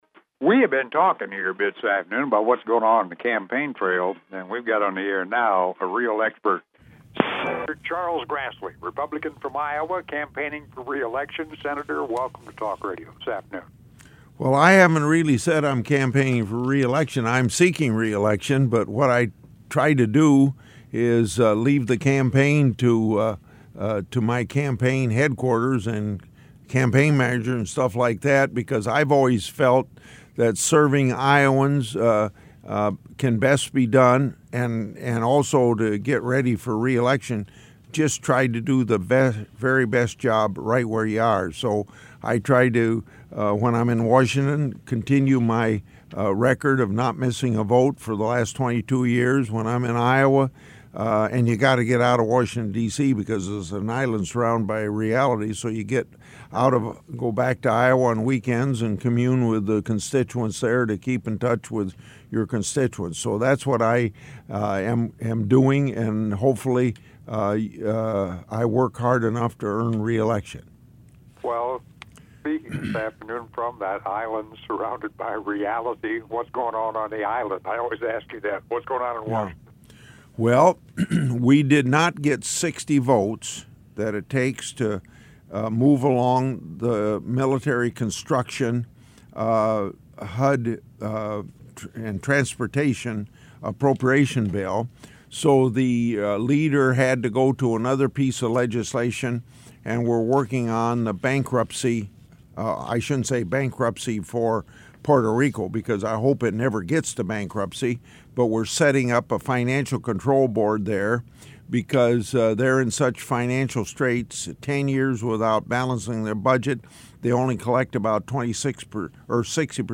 Public Affairs Program, 6-28-16 WOC.mp3